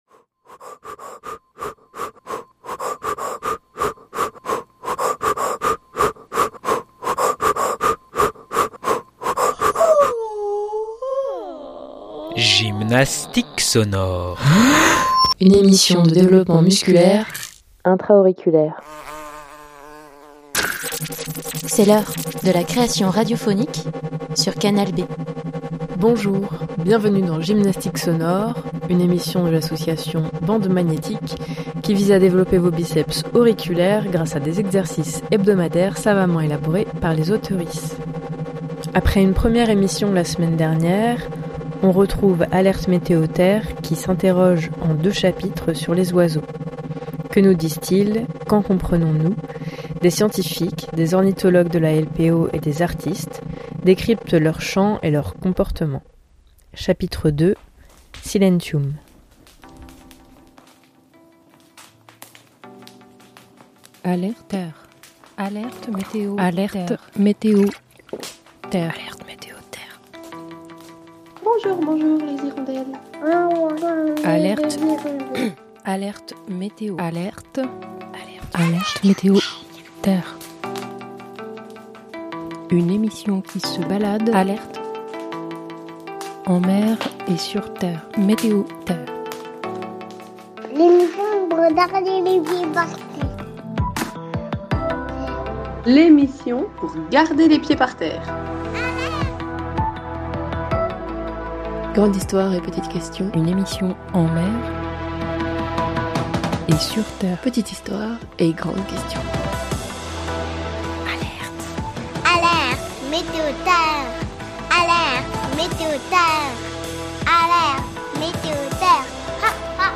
Le deuxième chapitre part à l'écoute de chercheurs au CNRS, ornithologues et observateurs d'oiseaux qui nous éclairent sur l'impact des changements climatiques sur la population des oiseaux.